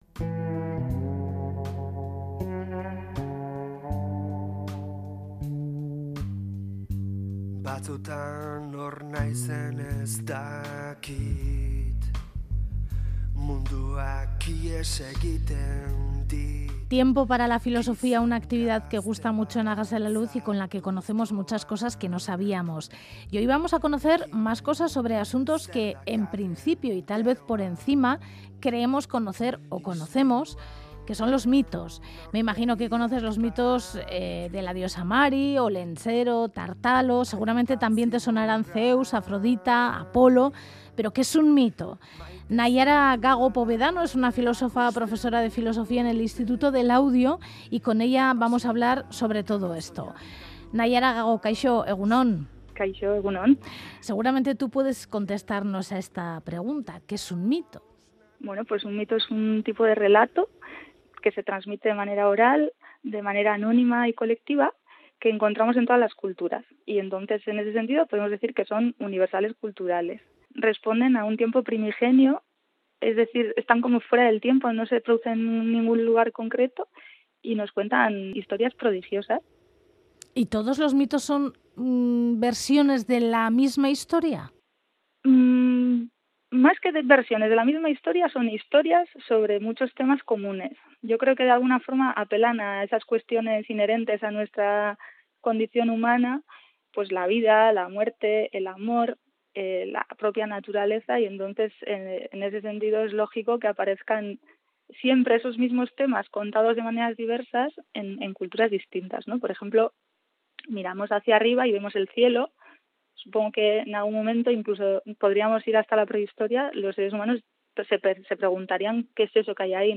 Audio: Hoy en Radio Euskadi hablamos con la filósofa